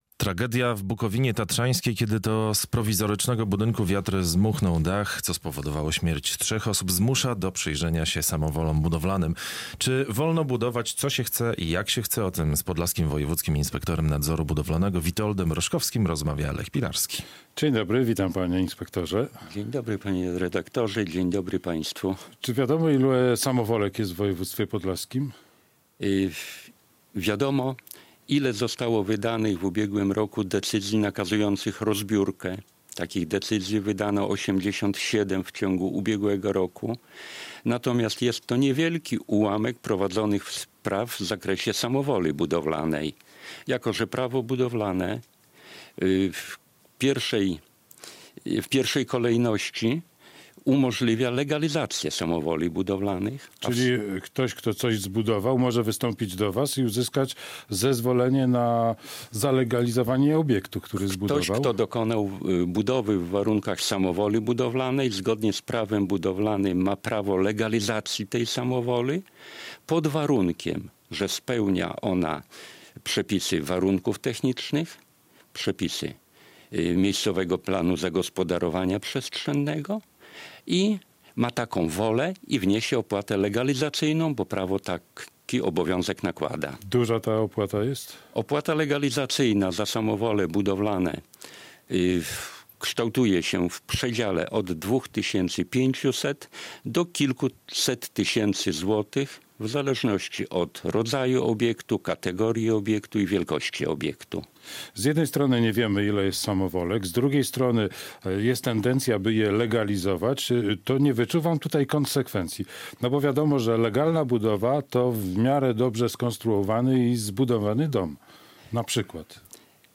Radio Białystok | Gość | Witold Roszkowski [wideo] - Podlaski Wojewódzki Inspektor Nadzoru Budowlanego
Podlaski Wojewódzki Inspektor Nadzoru Budowlanego